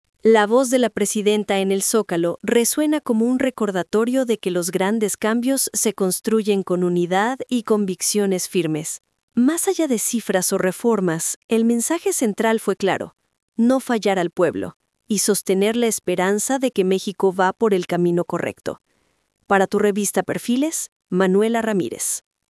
COMENTARIO EDITORIAL…